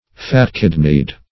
Search Result for " fat-kidneyed" : The Collaborative International Dictionary of English v.0.48: Fat-kidneyed \Fat"-kid`neyed\, a. Gross; lubberly.
fat-kidneyed.mp3